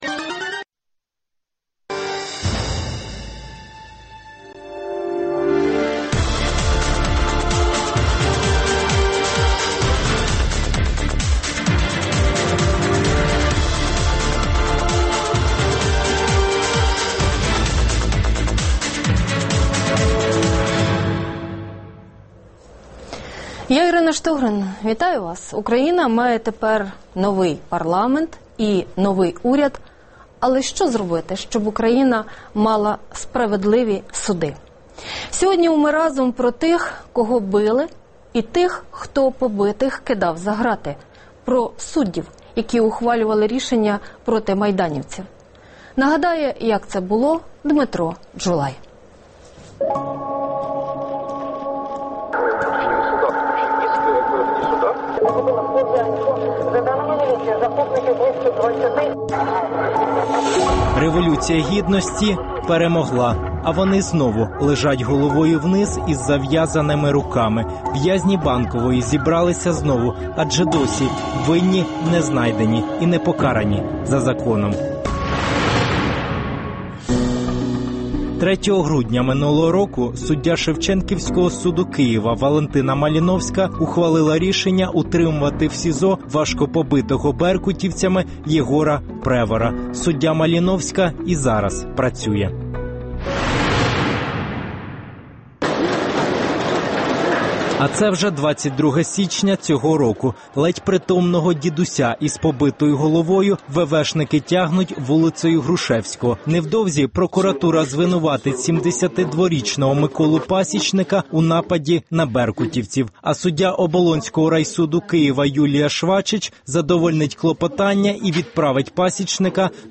Гість: Маркіян Галабала, заступник голови Тимчасової спеціальної комісії з перевірки суддів судів загальної юрисдикції